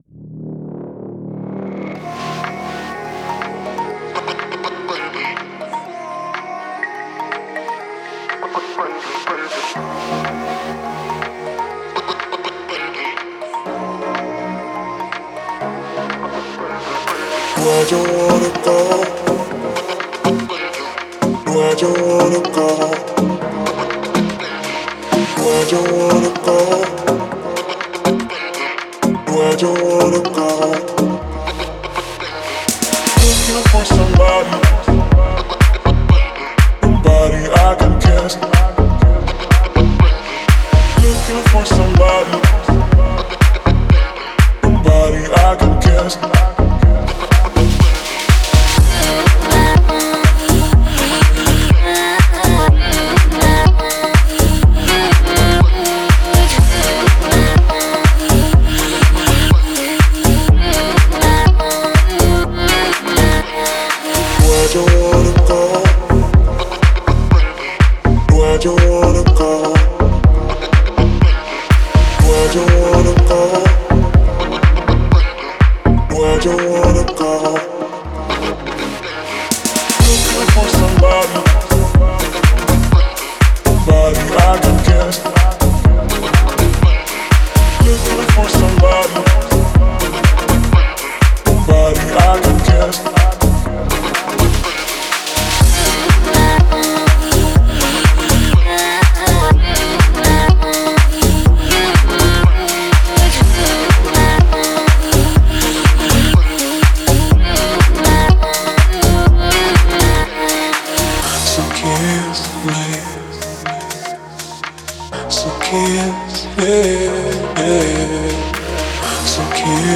это яркий R&B трек